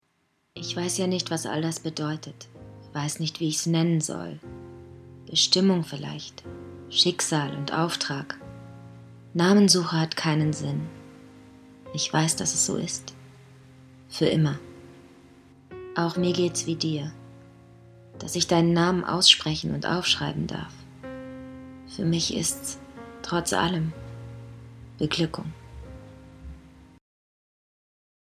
Sprechprobe: Sonstiges (Muttersprache):
Actress, Dubbing, Advertisement, Games, Audio Drama, Voice-Over, Native Speaker (German), English (US), Warm, Feminine, Sensitive, Clear, Laid-Back, Cool, Young, Fresh, Hip, Charming, Seductive, Provoking, Challenging, Dark, Mad, Angry, Wicked, Emotional, Understatement, Comical, Funny, Comic-Voice, Playful